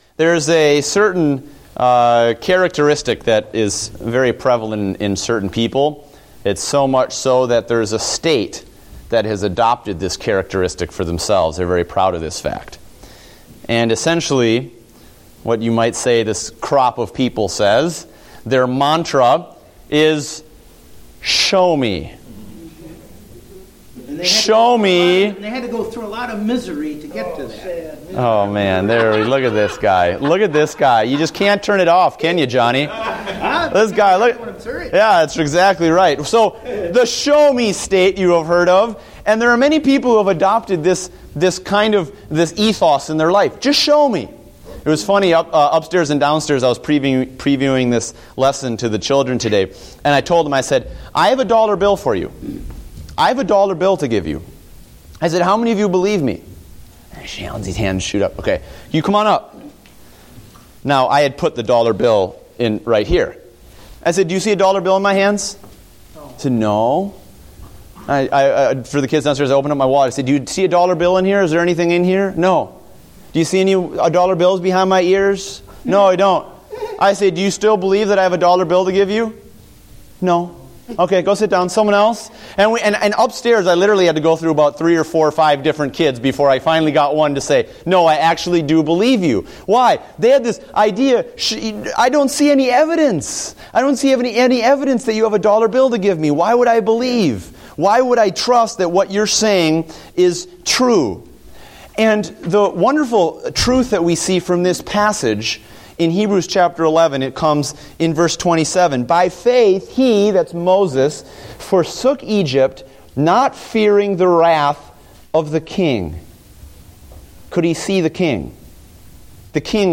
Date: November 16, 2014 (Adult Sunday School)